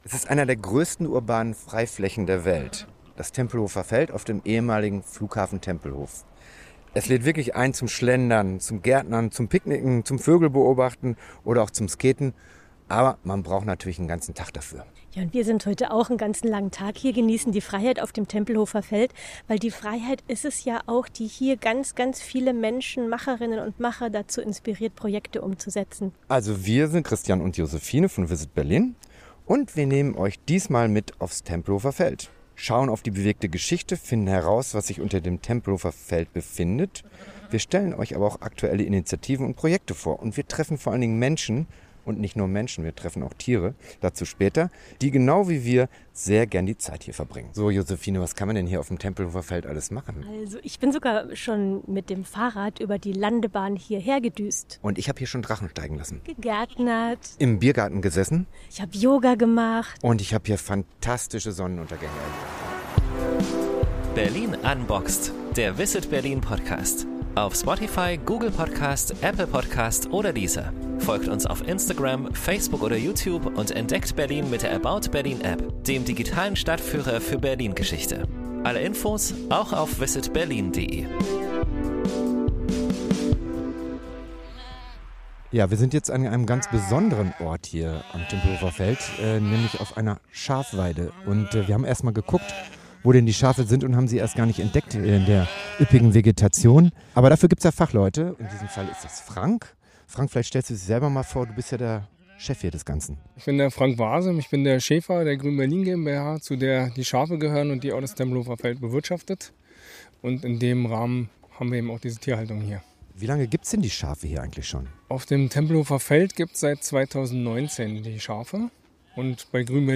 Wir besuchen in dieser Folge vier Menschen, die solche Projekte mitgestalten und erfahren mehr über den Naturraum Tempelhofer Feld und dessen bewegte Geschichte. Wir spüren den Wind, hören die Wiese, streicheln die Schafe und bekommen einen guten Einblick, was eine solche urbane Freifläche für Flora, Fauna und Klima bedeutet – und für die Menschen, die hier ihre Ideen verwirklichen dürfen.